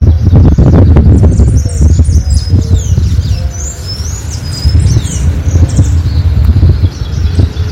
Celestino Común (Thraupis sayaca)
Encontrado en una plazoleta del pueblo, en Leandro Alem y Moreno. Estaba en lo alto de los árboles pero se lo escuchaba vocalizar con claridad.
Partido de Saladillo, provincia de Buenos Aires.
Nombre en inglés: Sayaca Tanager
Certeza: Vocalización Grabada